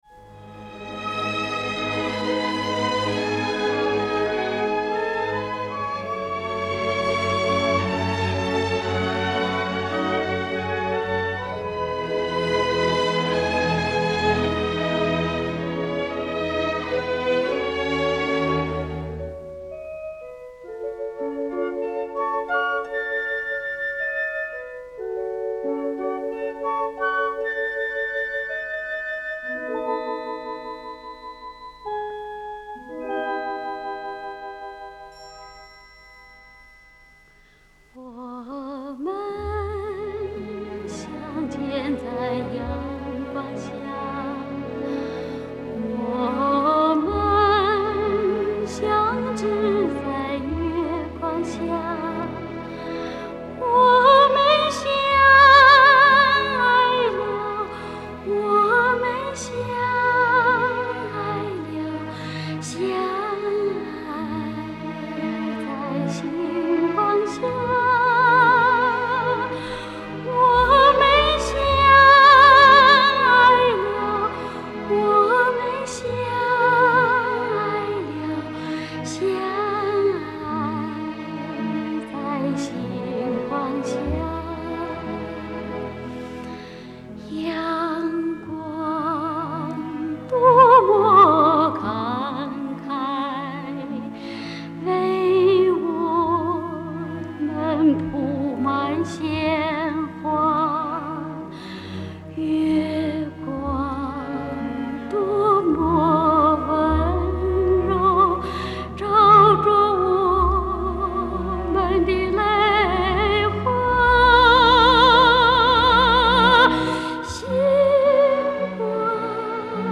1980年录音